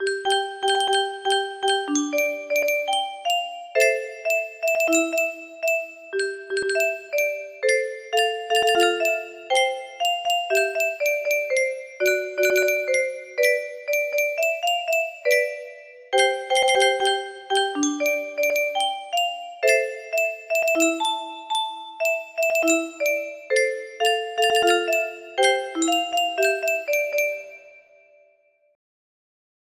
Barb music box melody